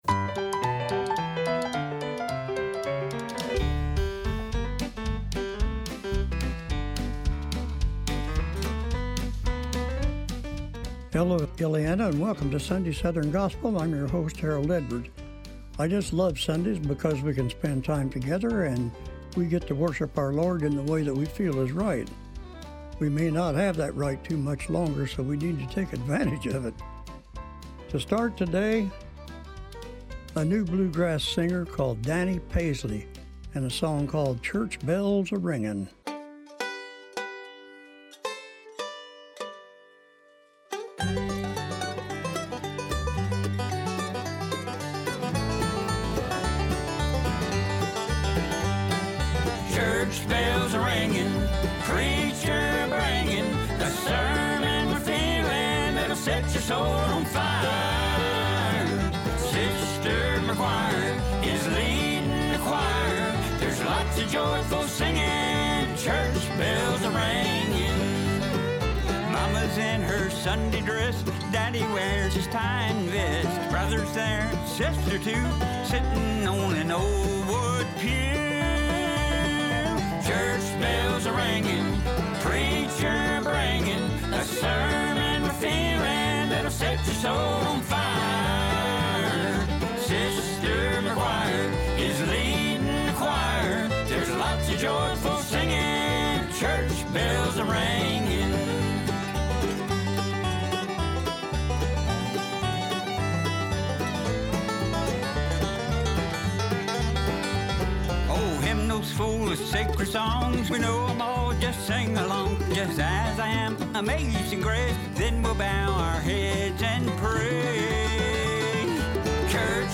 sunday-southern-gospel-8-31-25.mp3